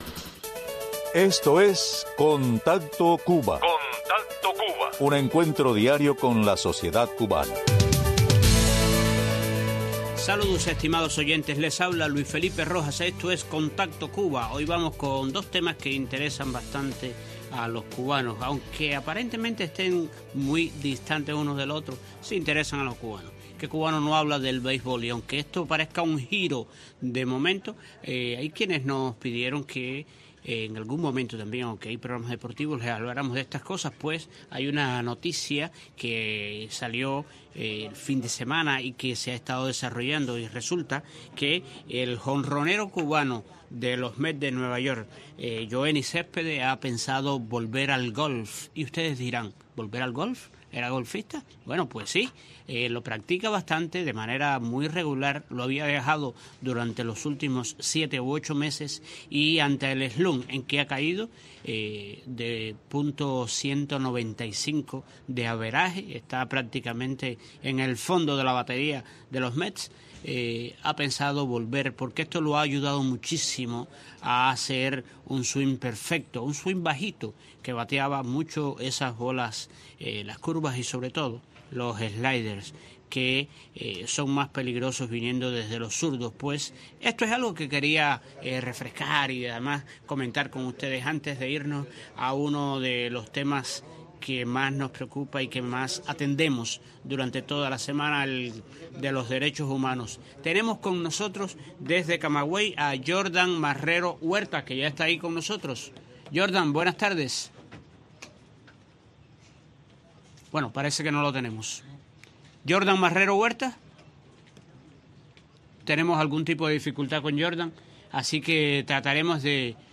Este programa se empeña en darles voz a los hombres y mujeres que hacen la Cuba del futuro, el país que buscamos hoy. Temas sociales, económicos y la agenda que la sociedad civil independiente comparte con la comunidad más cercana. Frecuencia: lunes a viernes 3:30 PM a 4:00 PM.